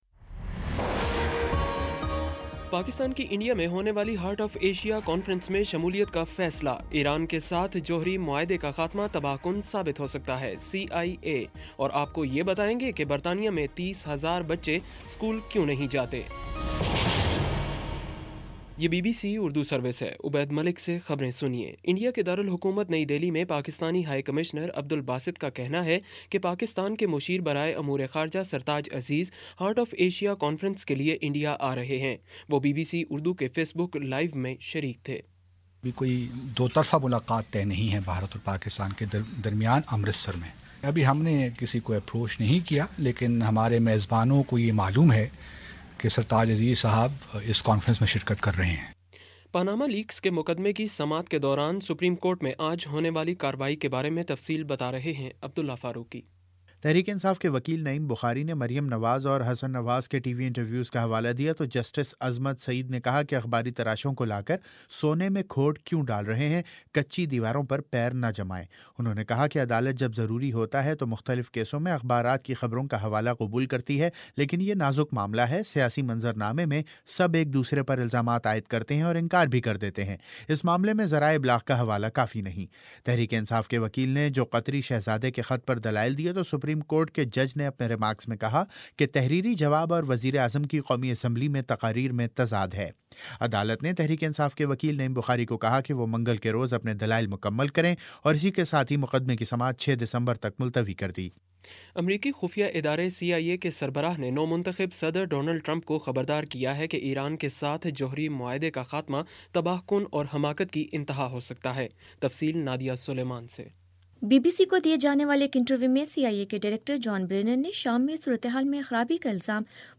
نومبر 30 : شام چھ بجے کا نیوز بُلیٹن